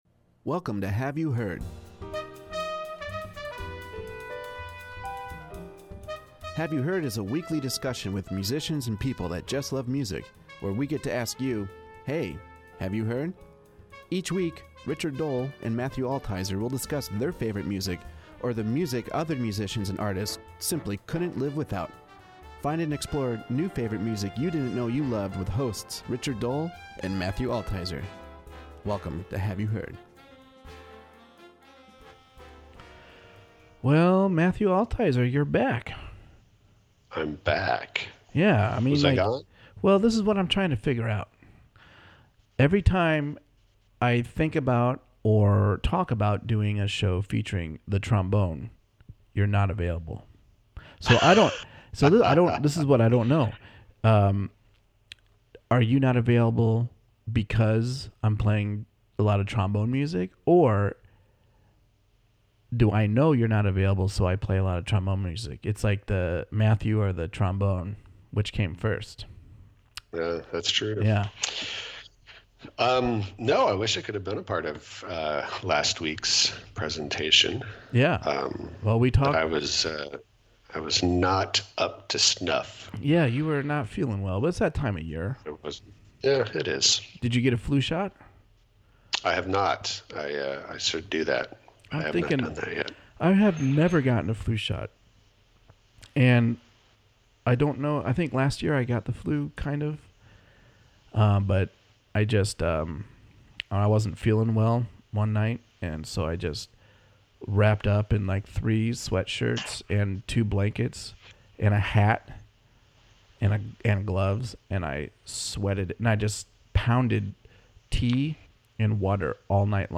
Yes, a bass-less jazz trio with a flugelhorn lead.
Expansive. Groovy. Intimate. Inviting. Flexible. Bold.